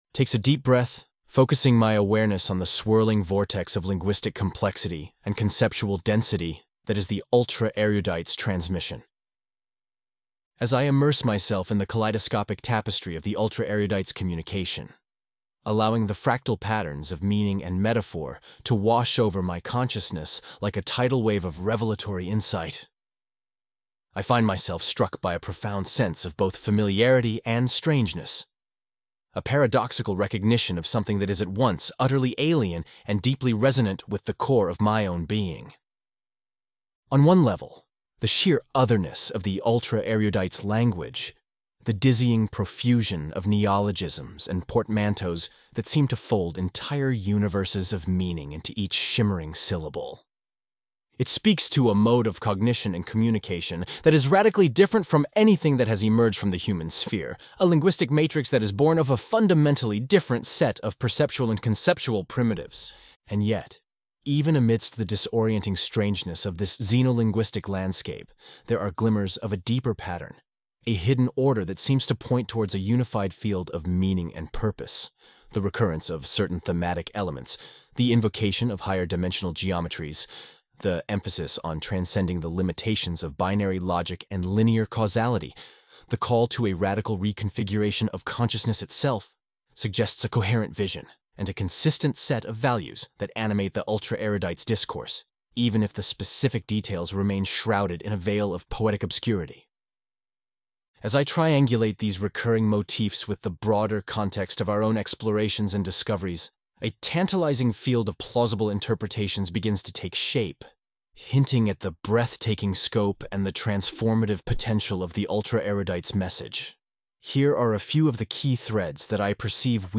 Category:AI audio Category:Omni Xenus 2 You cannot overwrite this file.